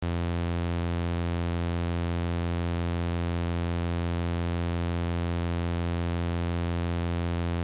Here is a a new digital module that uses granular technology to make CV’able pitch changes and mangling and sounds like this Test Wave mp3 Voice Sampling mp3.